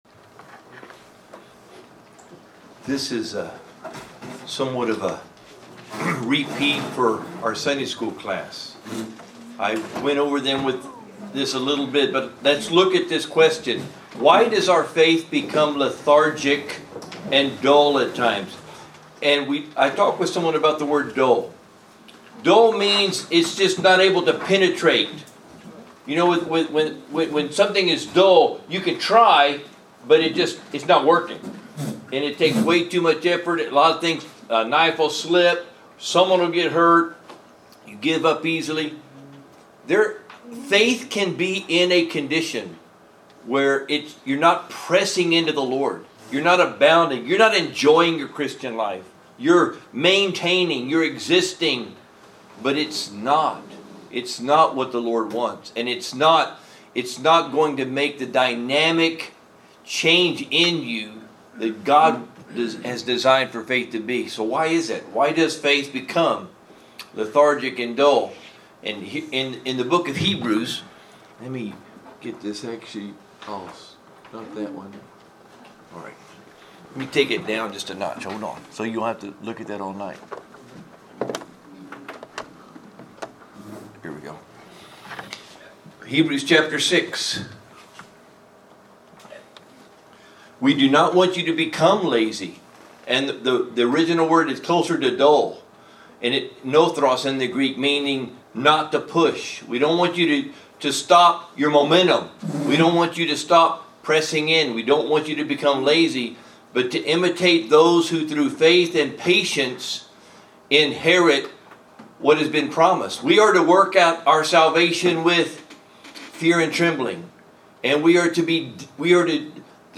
Wednesday Night Study - University Park Baptist